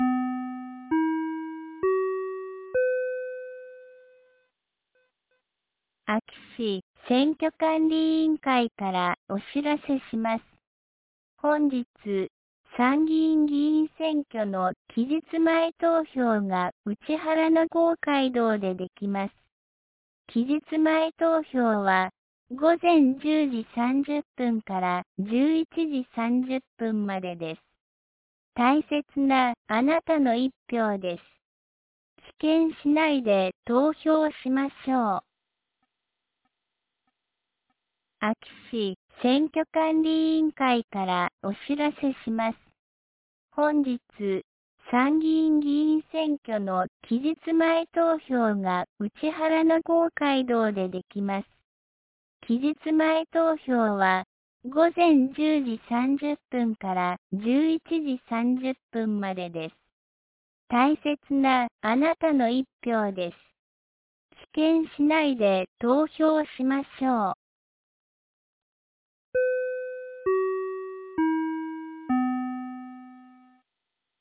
2025年07月17日 10時01分に、安芸市より井ノ口へ放送がありました。